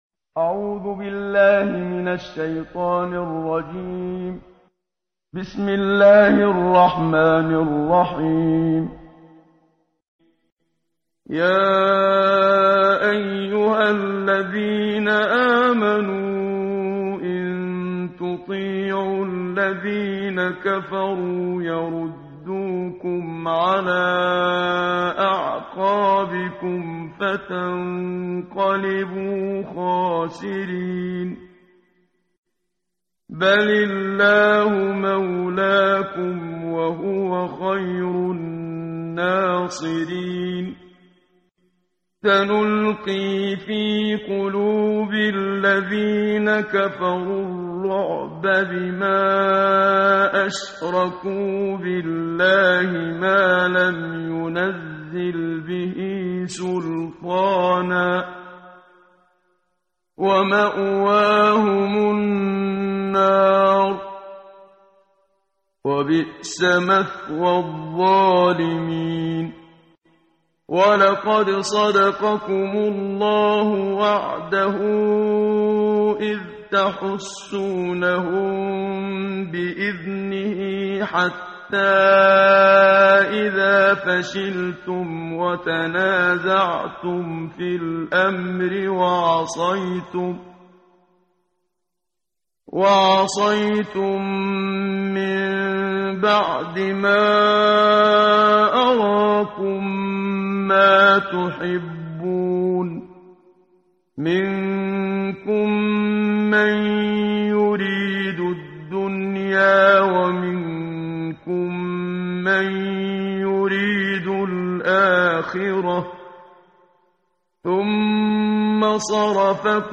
قرائت قرآن کریم ، صفحه 69، سوره مبارکه آلِ عِمرَان آیه 149 تا 153 با صدای استاد صدیق منشاوی.